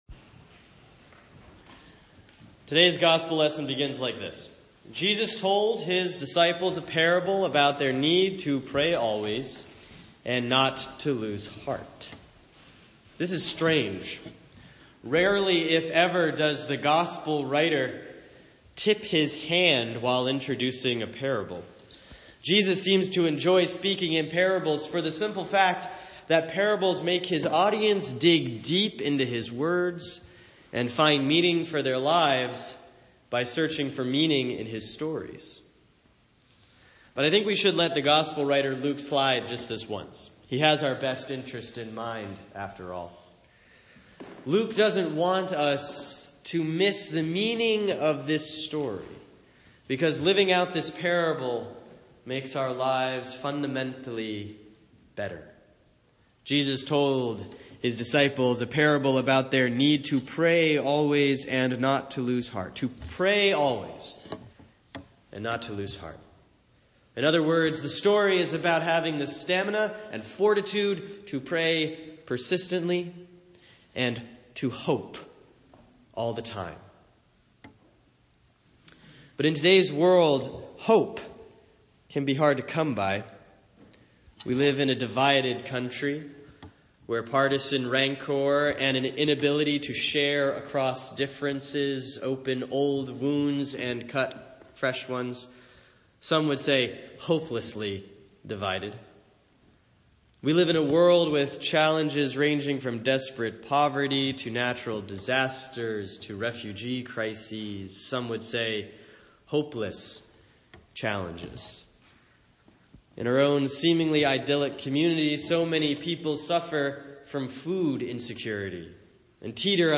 Sermon for Sunday, October 23, 2016 || Proper 25C || 2 Timothy 4:6-8, 16-18; Luke 18:9-14 https